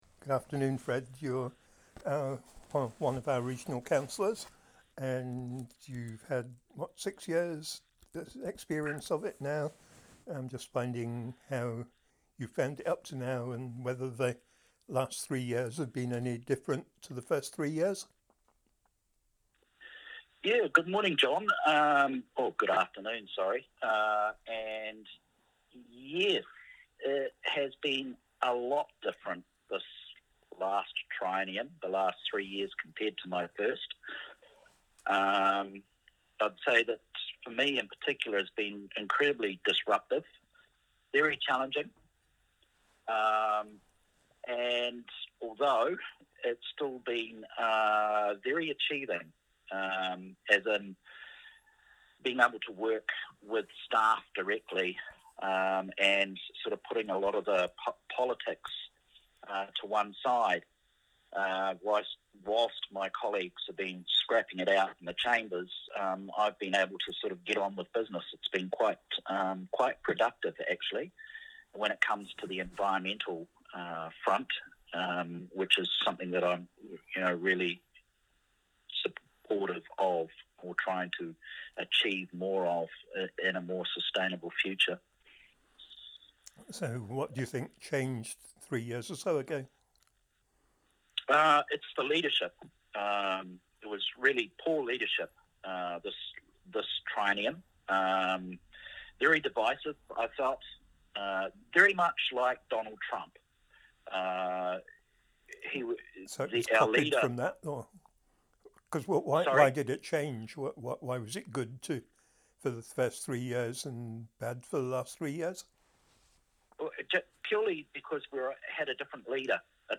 Fred Litchwark - Regional Councillor